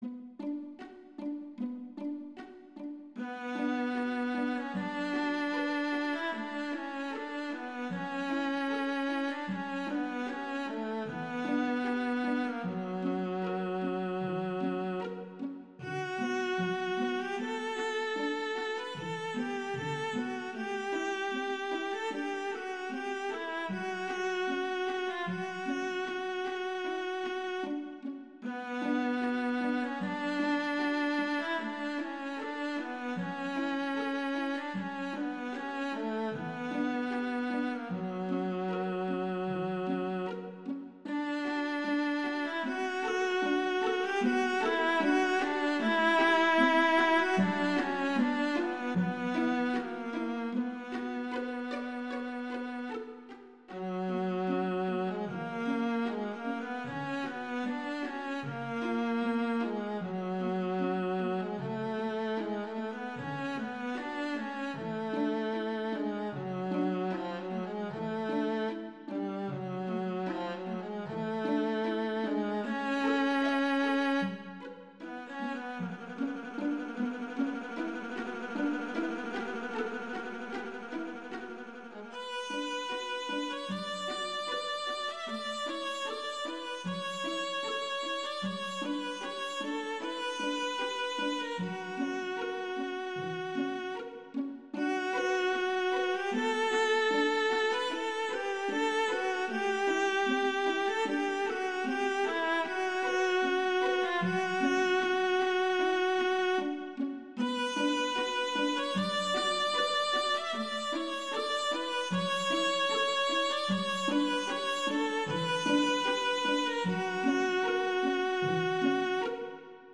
Instrumentation: two violas
classical, french
B minor
♩=76 BPM